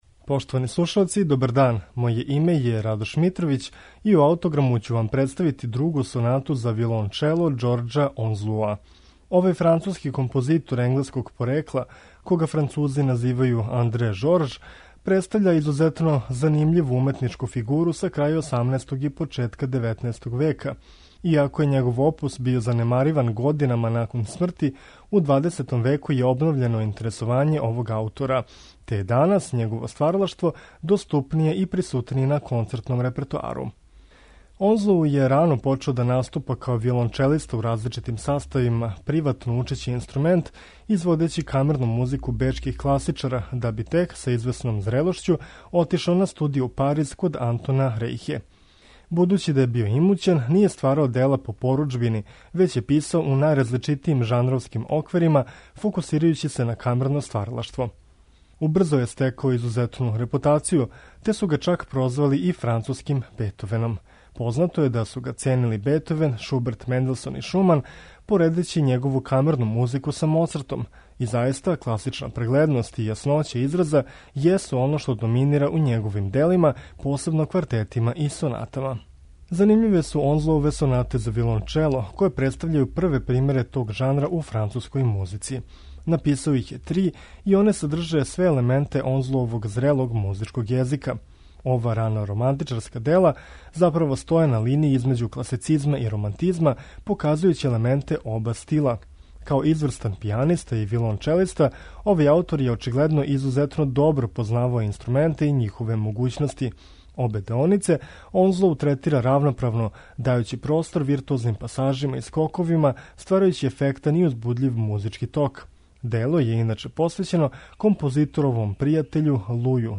ЏОРЏ ОНЗЛОУ: СОНАТА ЗА ВИОЛОНЧЕЛО